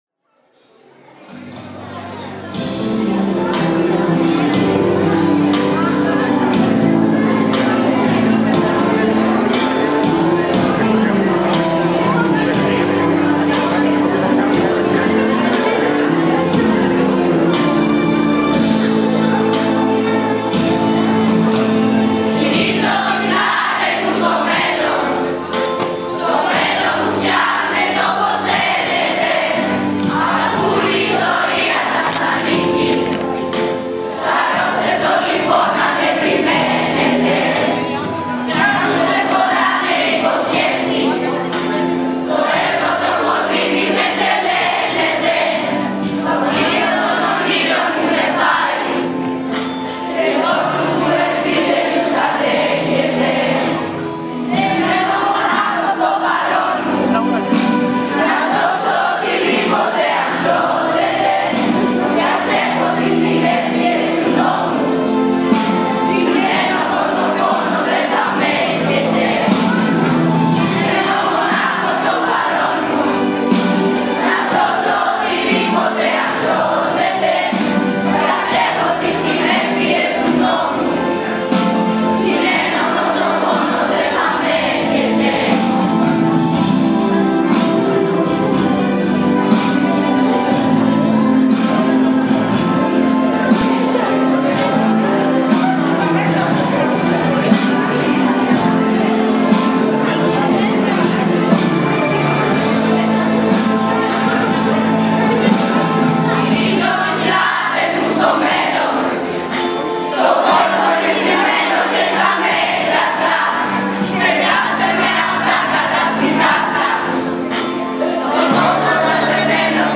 Ολοκληρώνοντας τη δημοσίευση για την αποχαιρετιστήρια εκδήλωση, παραθέτουμε αποσπάσματα από τα τραγούδια που τραγούδησαν οι μαθητές μας.